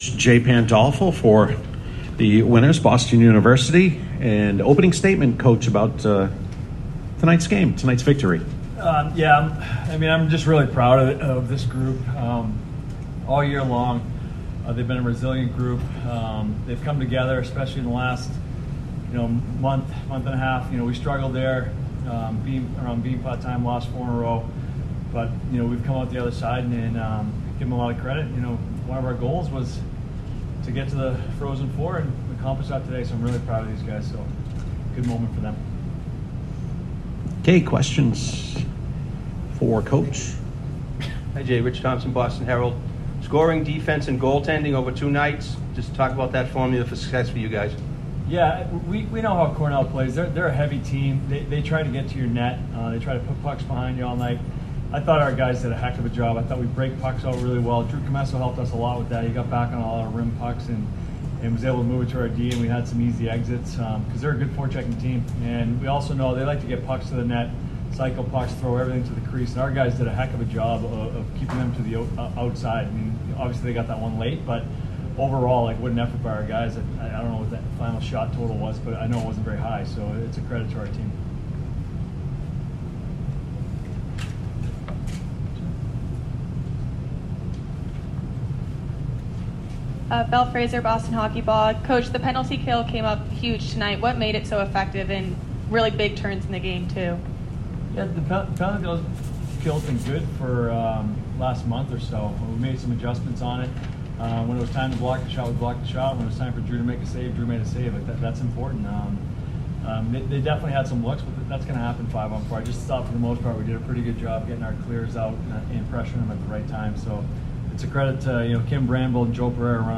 CornellPostgame.mp3